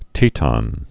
(tētŏn, tētn)